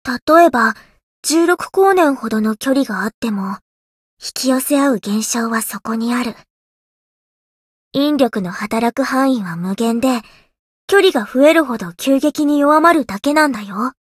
灵魂潮汐-西勒诺斯-七夕（相伴语音）.ogg